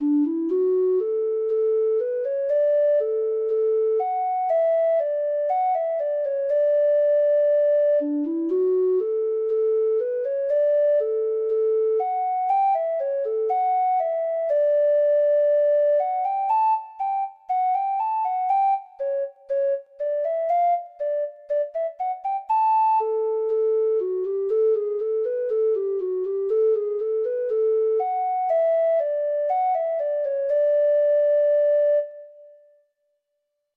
Traditional Music of unknown author.